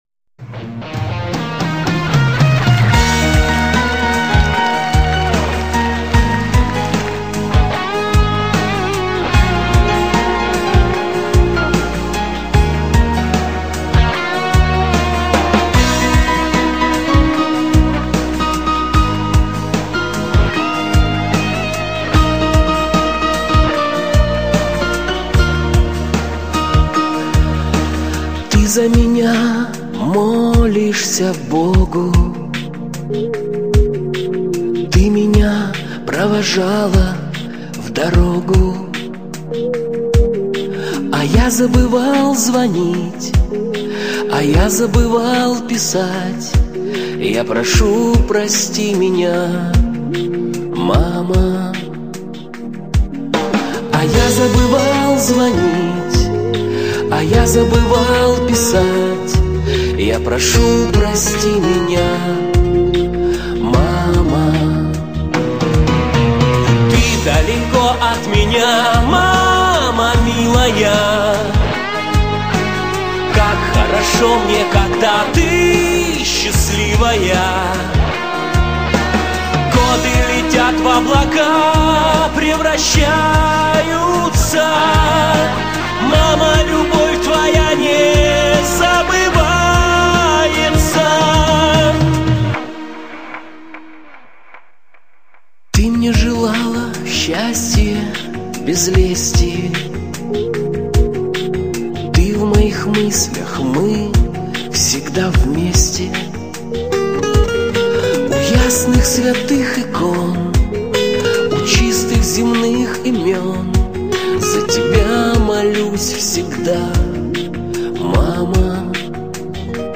on 2015-12-27 - Молитвенное пение